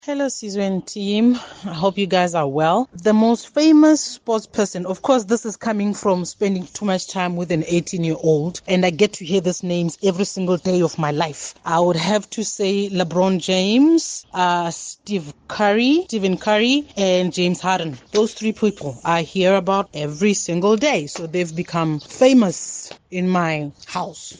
Kaya Drive listeners picked their most famous sporting personalities: